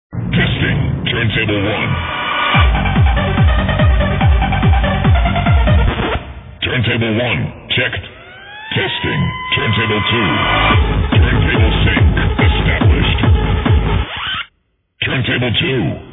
dark male voice says: